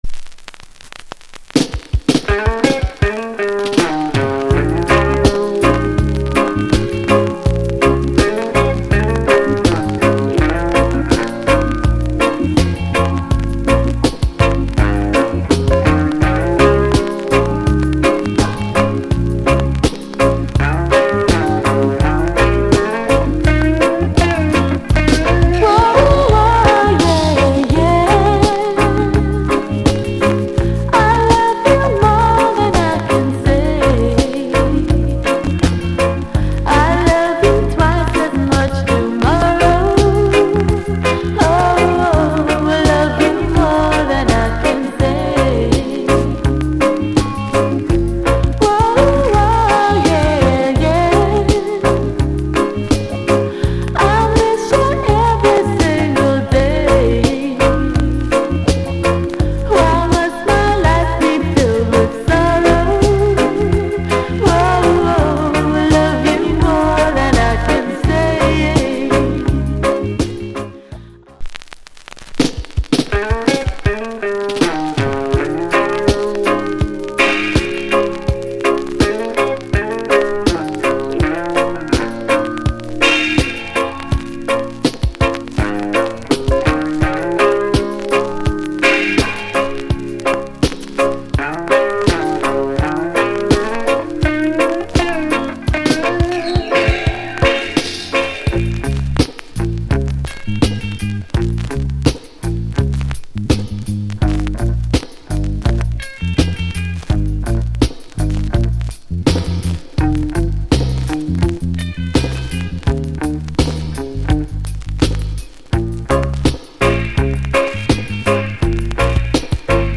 Lovers Mood